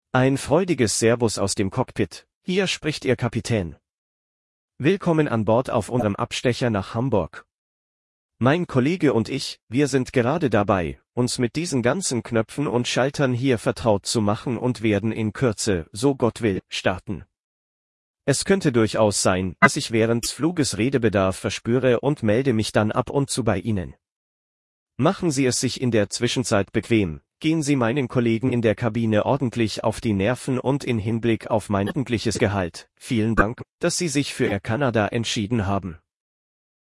BoardingWelcomePilot.ogg